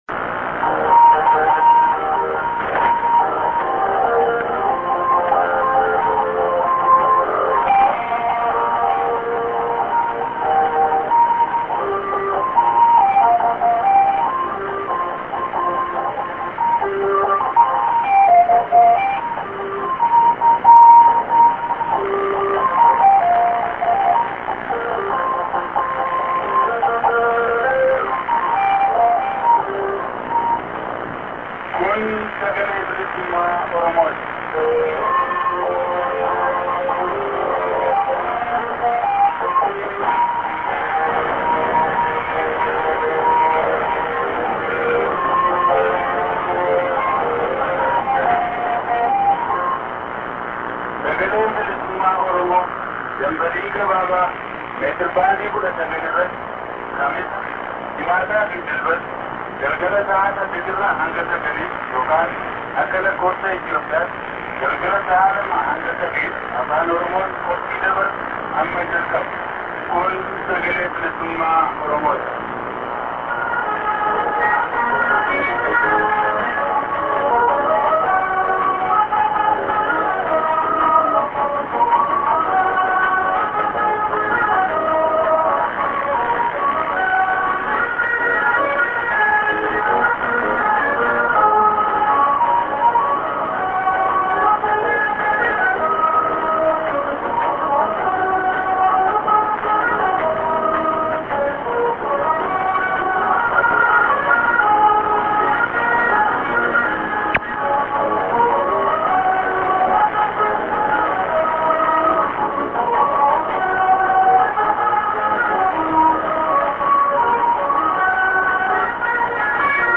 St. ST->ID:--oromo--(man->)->ST->ID(man)->music->prog->